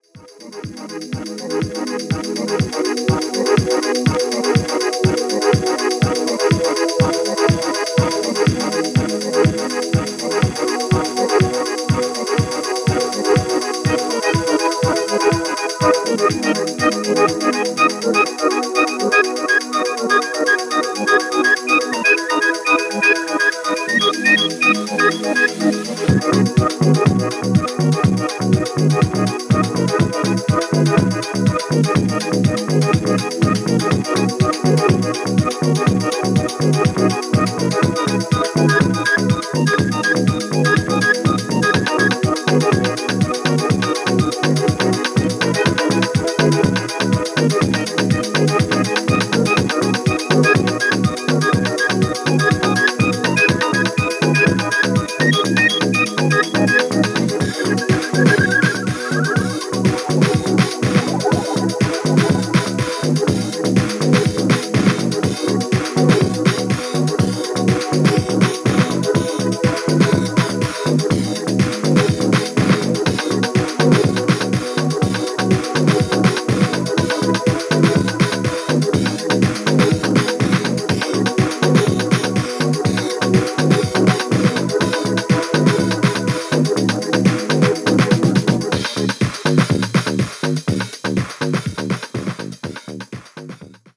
シカゴ感漂う感じがステキ！！！